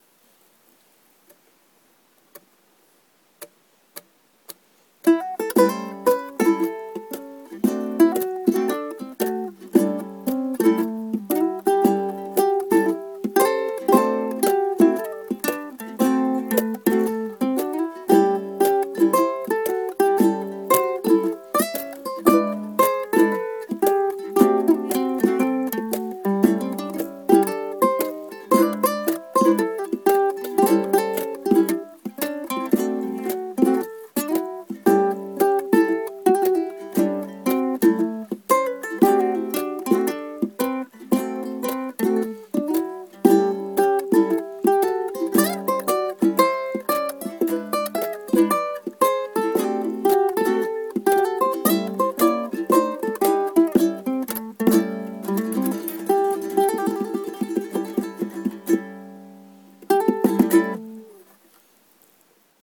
ブルースセッション音源をアップしておきます。
使用楽器はウクレレのみです。
内蔵マイクなのでノイズも結構のってるけど、内蔵マイクだけでここまで綺麗に録音できたら充分でしょう。
ukulele.m4a